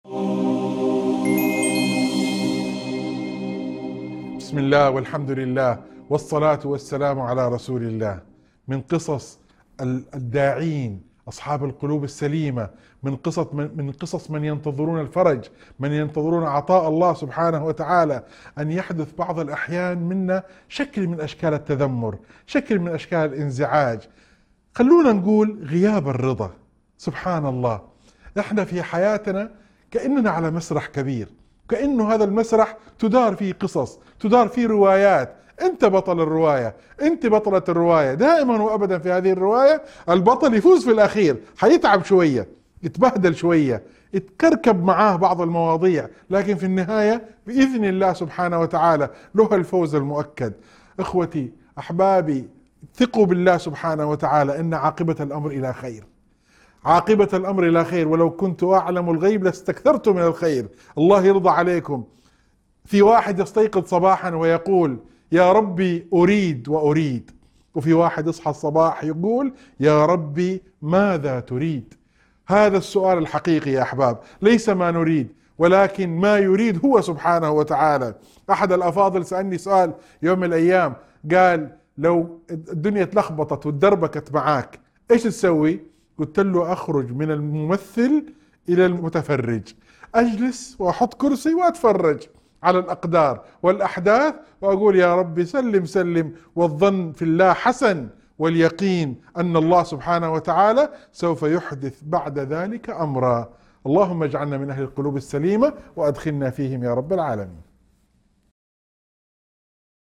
موعظة مؤثرة تشبه الحياة بمسرح كبير، حيث يكون الإنسان بطلاً في روايته التي ينتهي نصرها بالرضا والتسليم لله. تذكر الموعظة بأهمية الرضا بقضاء الله وحسن الظن به، والانتظار للفرج مع اليقين بأن العاقبة ستكون للخير بإذن الله.